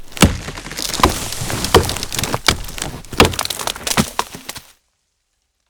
chop.wav